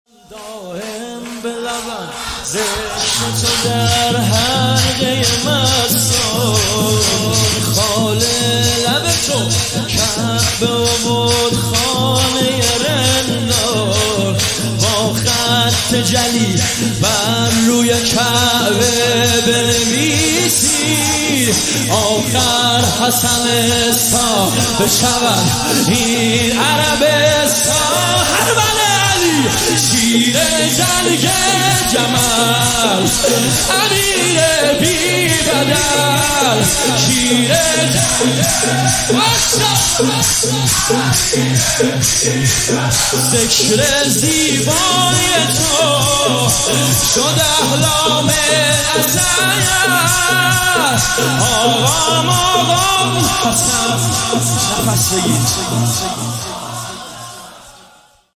مداحی
شهادت امام صادق(ع) هیئت ام ابیها(س)قم